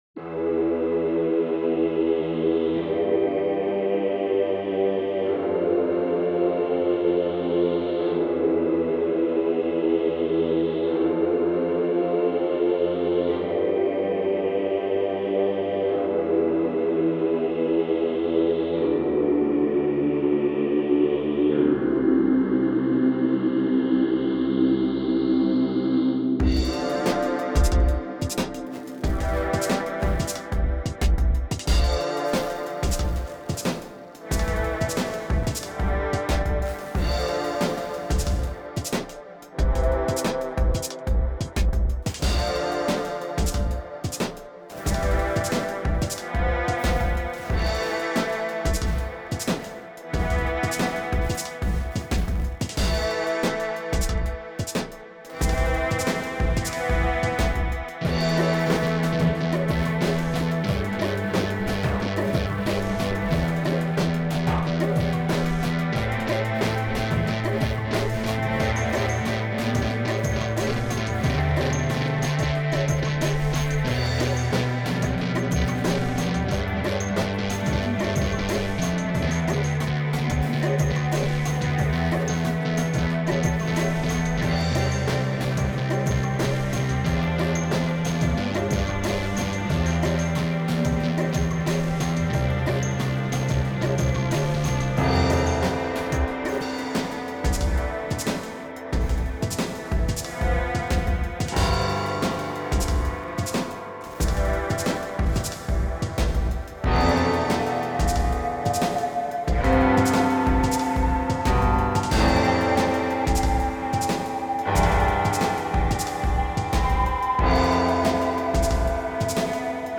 Foggy Desolate Town BG Music - Incidental Music and Soundtracks - Young Composers Music Forum